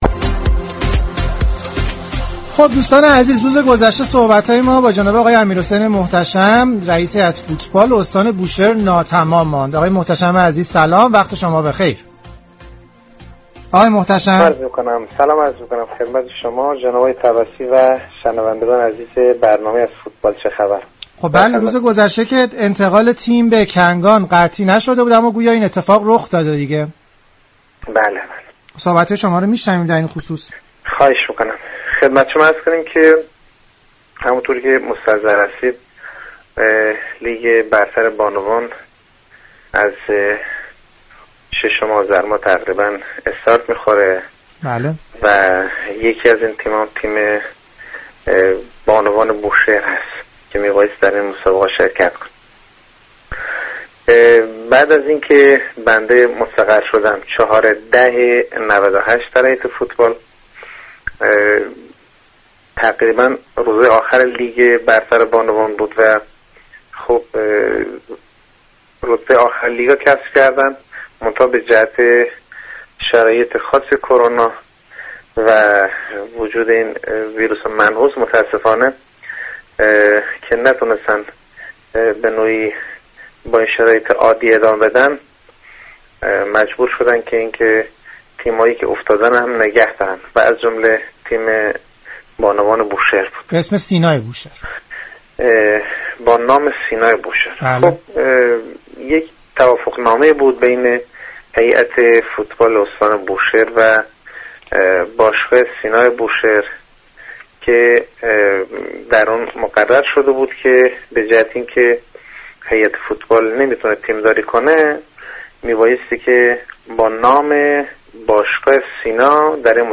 برنامه زنده "از فوتبال چه خبر؟"